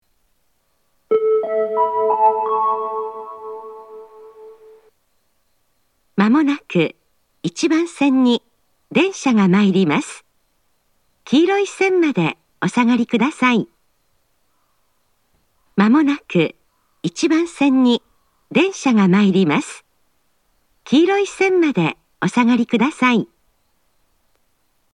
自動放送タイプ
仙石型（女性）
接近放送